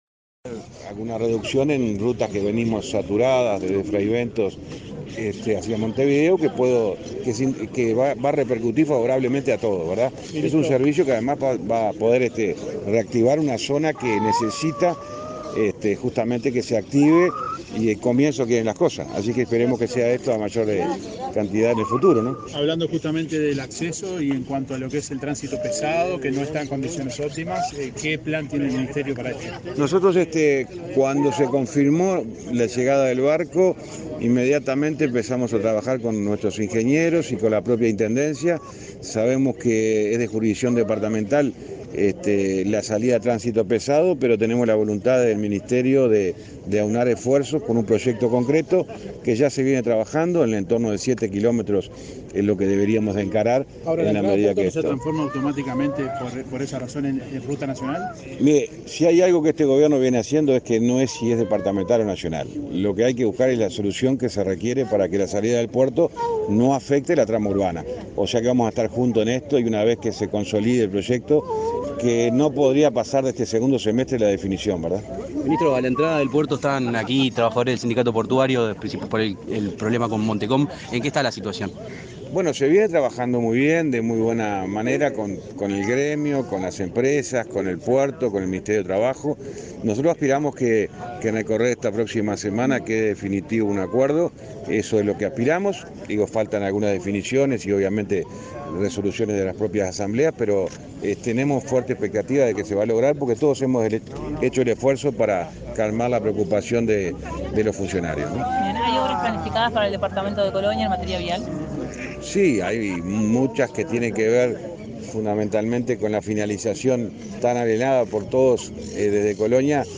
Declaraciones a la prensa del ministro de Transporte y Obras Públicas, José Luis Falero
Antes del evento, el jerarca efectuó declaraciones a la prensa.